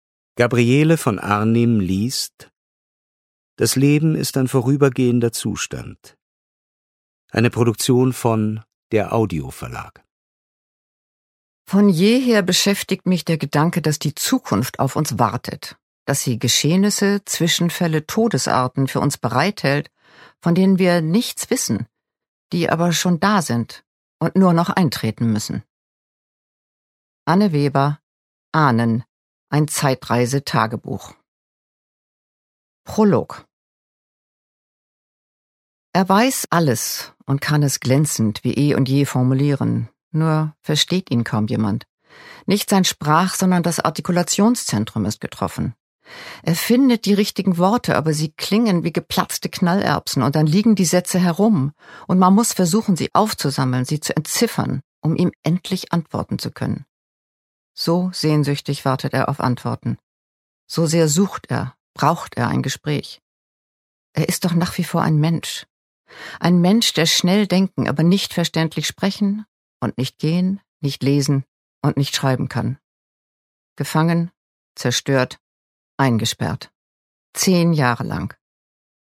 Das Leben ist ein vorübergehender Zustand Ungekürzte Autorinnenlesung